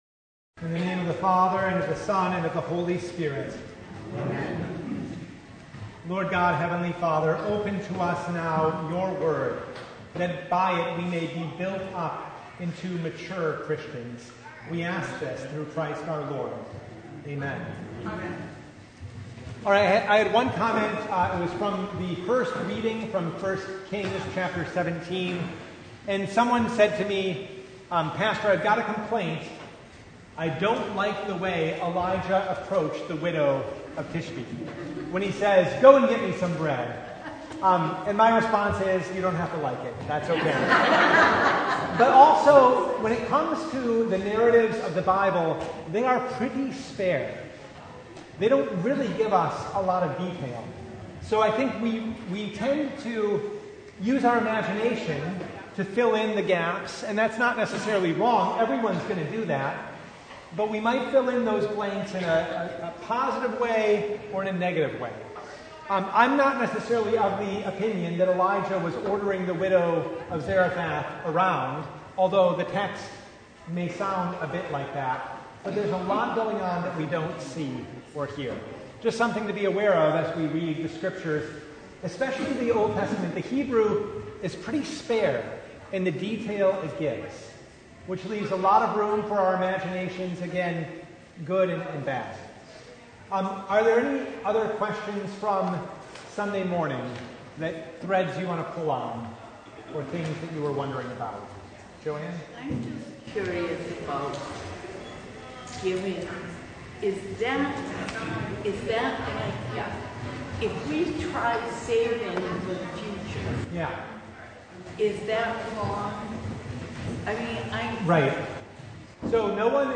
1 Corinthians 4:1-7 Service Type: Bible Hour Topics: Bible Study « All She Had The Second Last Sunday before Advent